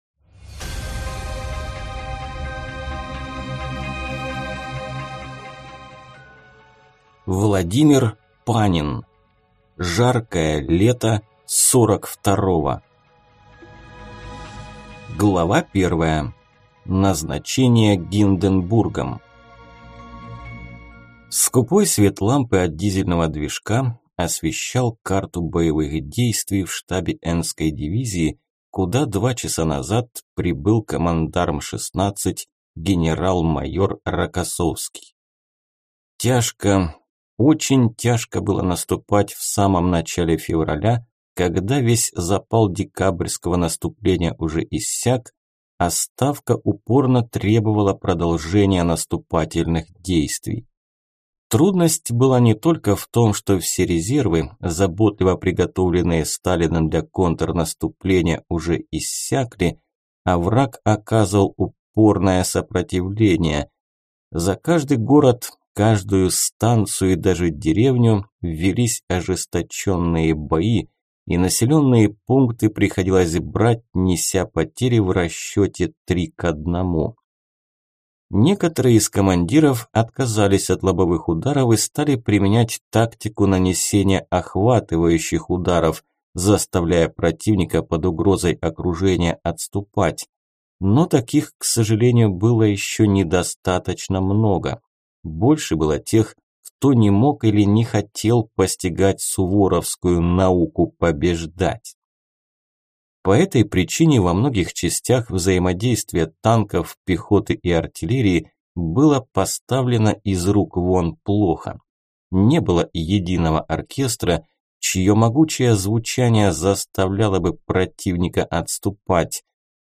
Aудиокнига Жаркое лето сорок второго